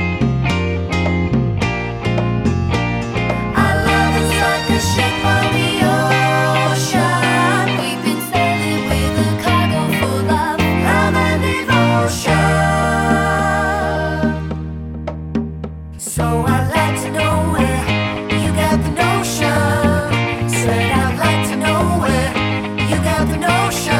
no drum kit with vocals Disco 3:04 Buy £1.50